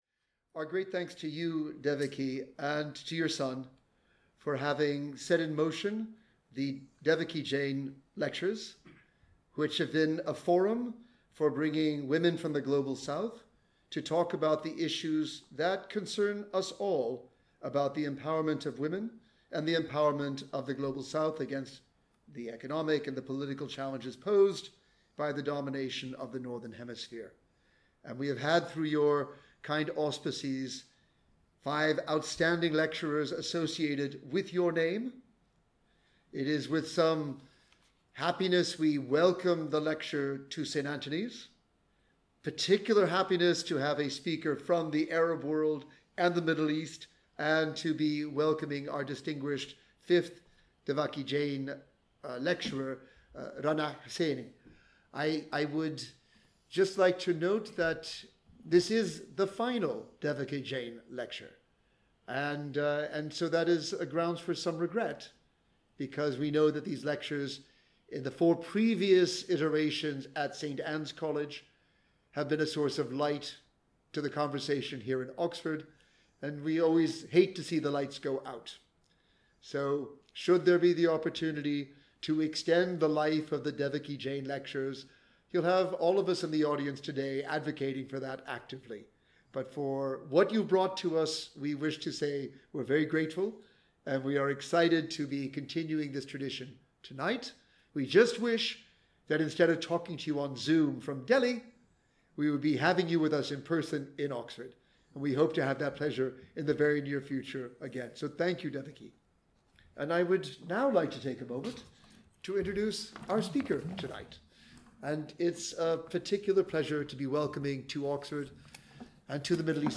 reflects on her work and career in the first of two lectures marking the 10-year anniversary of the Investcorp Building.